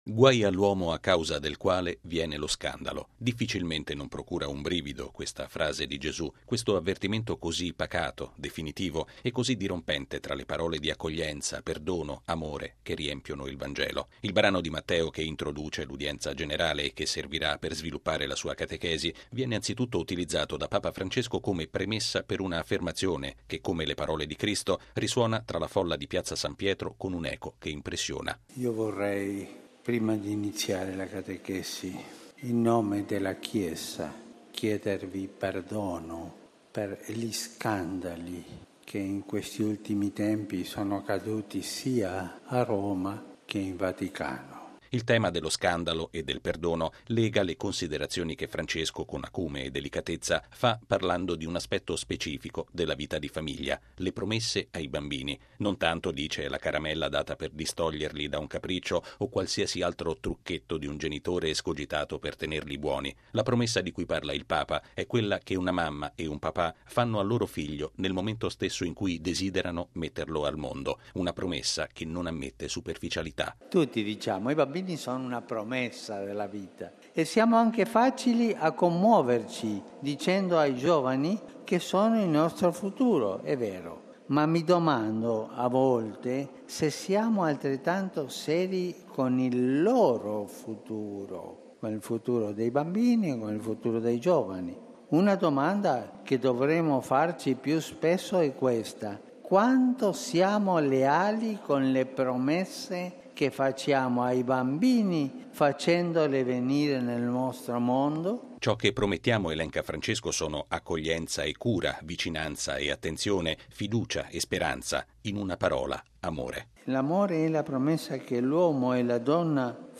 Il Papa ha iniziato con queste parole l’udienza generale di stamattina in Piazza San Pietro, dedicata a una riflessione sulle “promesse” che i genitori fanno ai loro figli mettendoli al mondo e sulle quali, ha detto, Dio veglia.